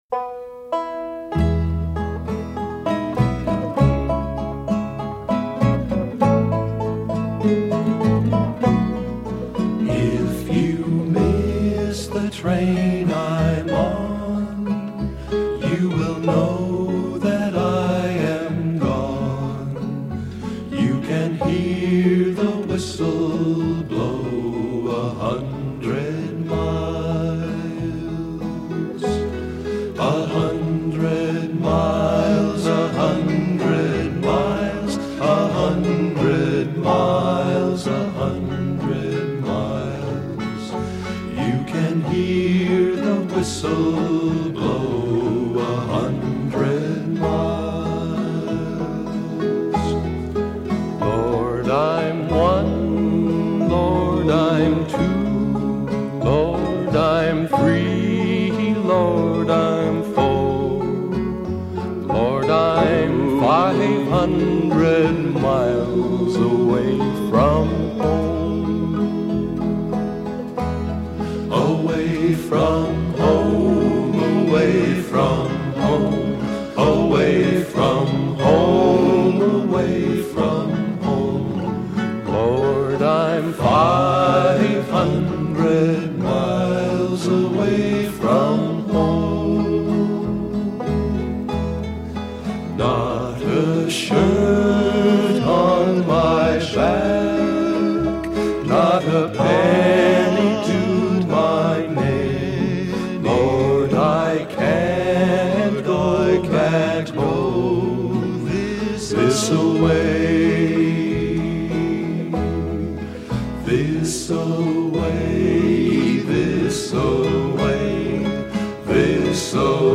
旋律优美的流行民歌是他们的主要曲风。